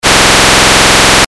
Pseudo-bruit à 10 bits
• horloge 22 kHz
Le spectre en fréquence du pseudo-bruit que vous entendez actuellement a l'allure suivante (Fig. 1) (signal audio échantillonné à 22 kHz, 8 bits, mono).
Il s'agit d'un spectre de raies dont la fondamentale vaut 21,5 Hz.
Dans ce cas la période vaut 47 ms (1023/22kHz), ce qui correspond à 21,5 Hz.